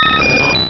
Cri de Roucoups dans Pokémon Rubis et Saphir.